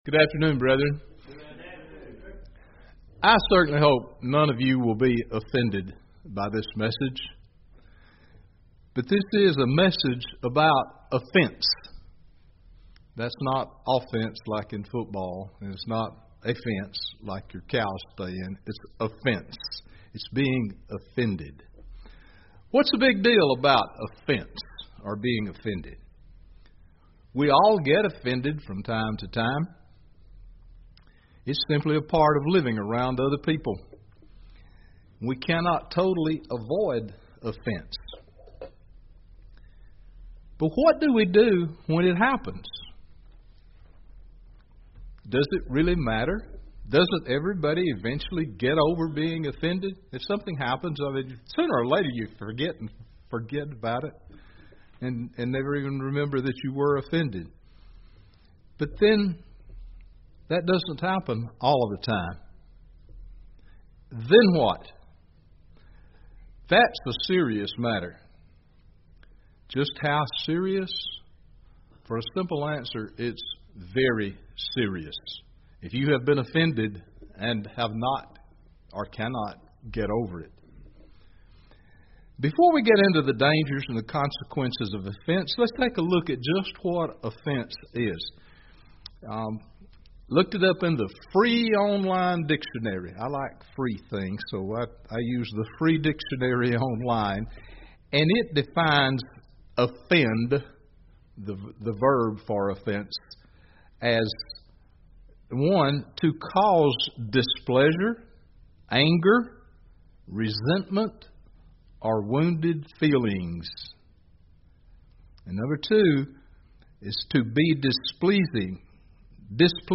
UCG Sermon Studying the bible?
Given in Gadsden, AL